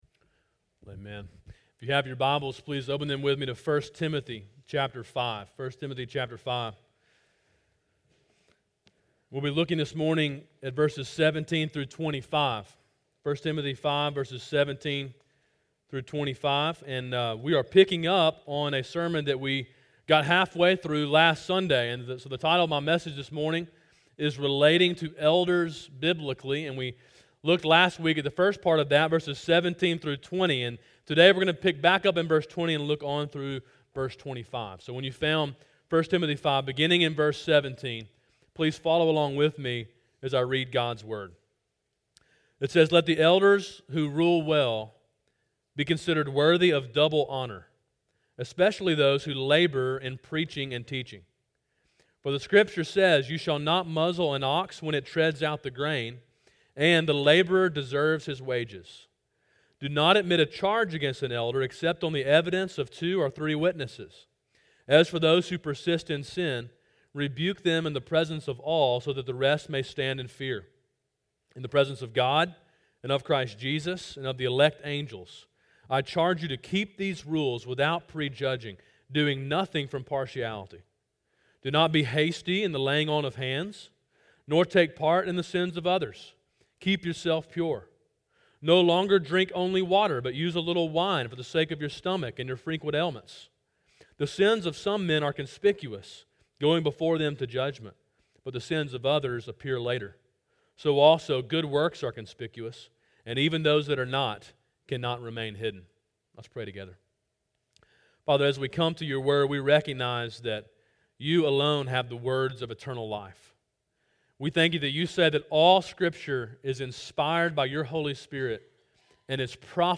A sermon in a series on 1 Timothy.